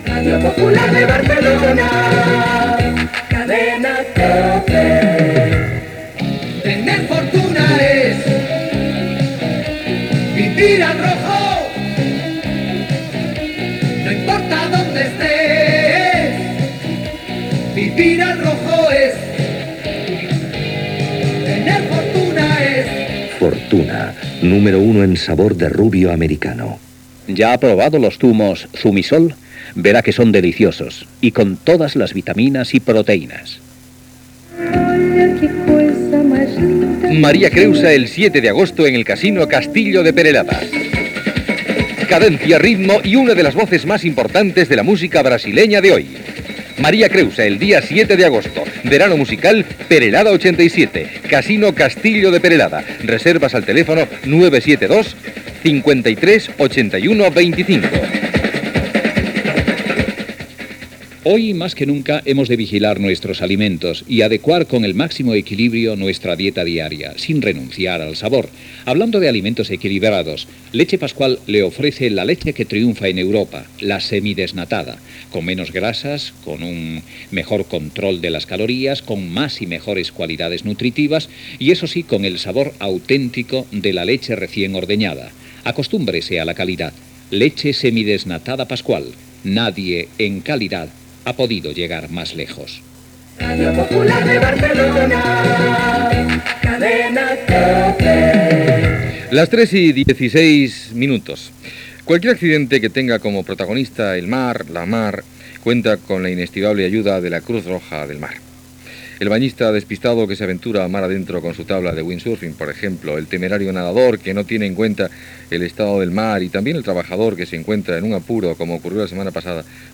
Indicatiu, publicitat i comentari sobre la Creu Roja del mar.